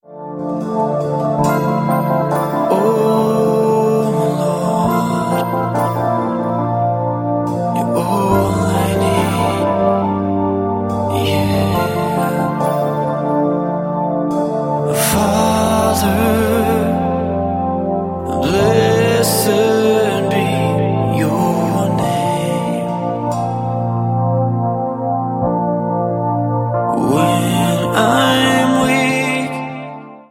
energiegeladenen Lobpreis
• Sachgebiet: Praise & Worship